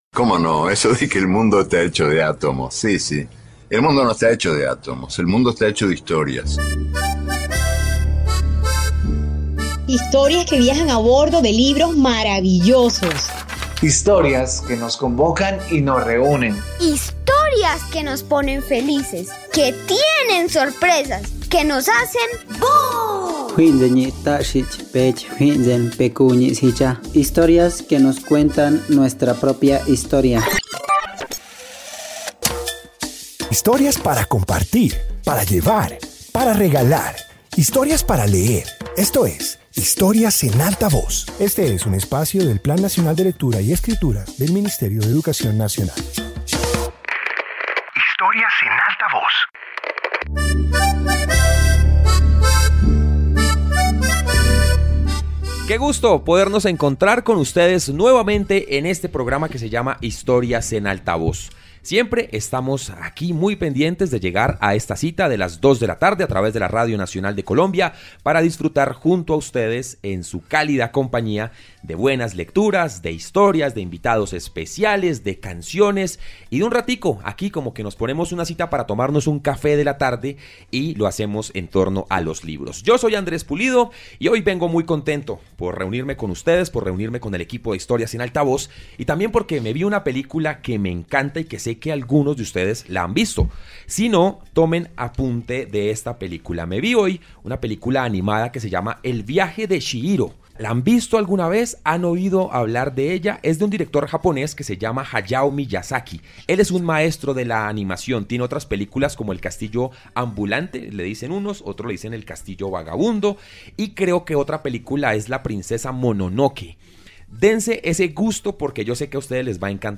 Introducción Este episodio de radio presenta historias del continente asiático. Comparte relatos sobre costumbres, expresiones culturales y tradiciones que muestran la diversidad de esta región.